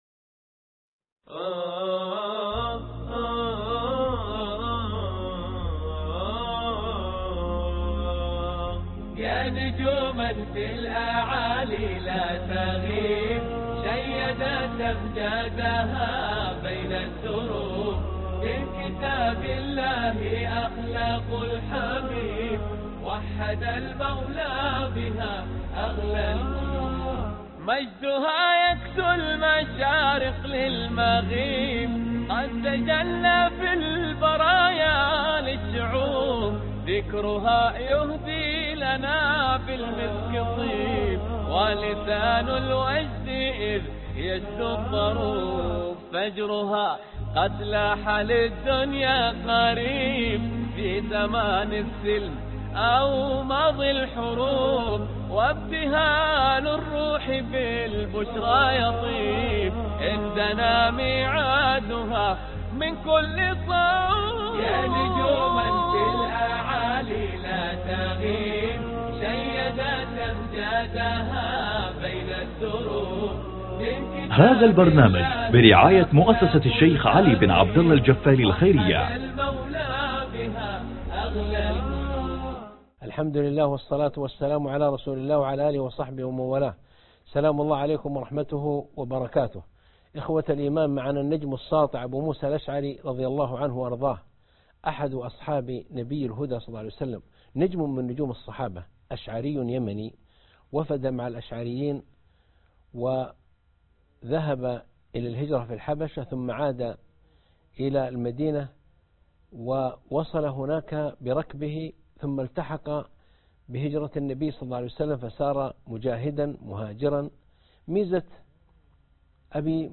الدرس 11 (أبو موسى الأشعري رضي الله عنه) مع النجوم - الدكتور عائض القرنى